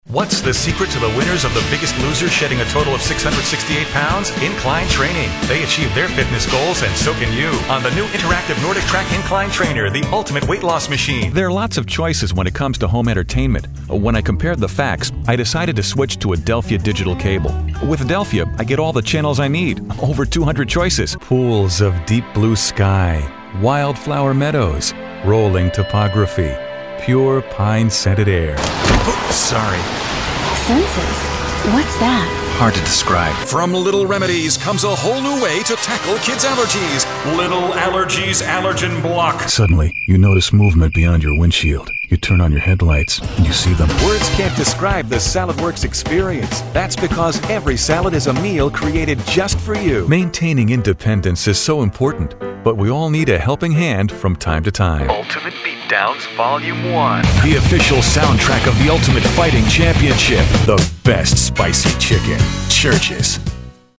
Male Voice Over Talent